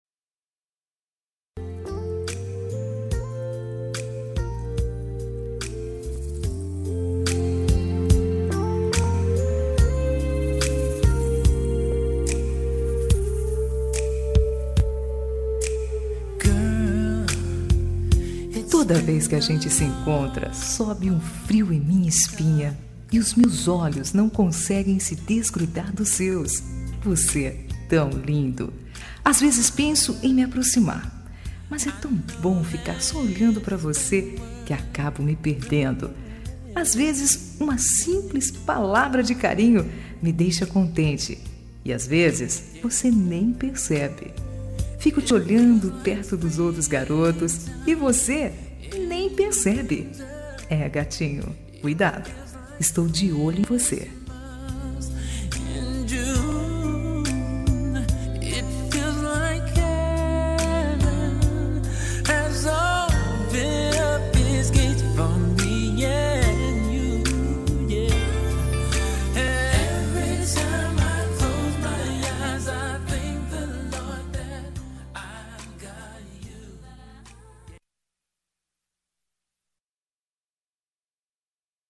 Telemensagem de Paquera – Voz Feminina – Cód: 2134 – Bonita